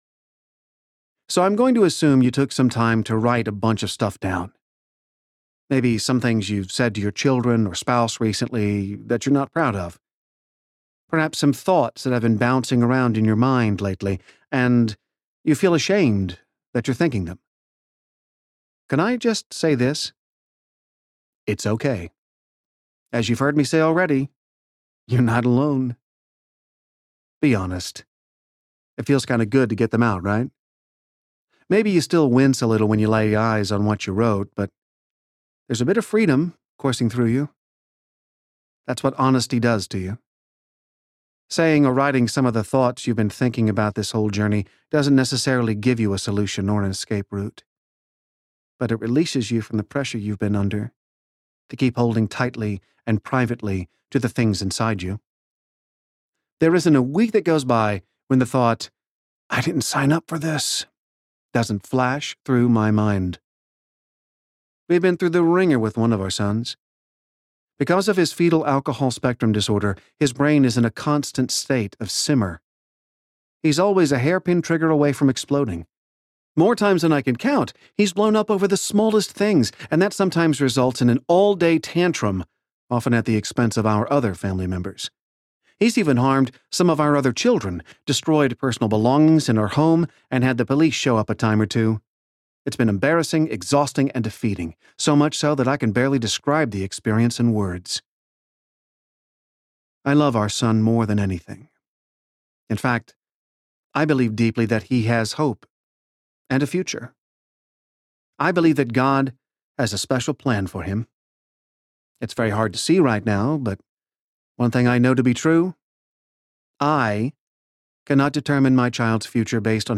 Confessions of an Adoptive Parent Audiobook
Narrator